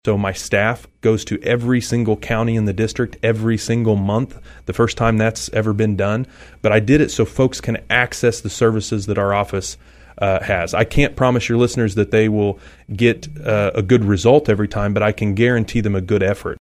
In fact, LaTurner — a recent guest on KVOE’s Morning Show — says he is now having mobile office hours across his coverage area on a stepped-up schedule.